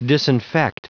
Prononciation du mot disinfect en anglais (fichier audio)
disinfect.wav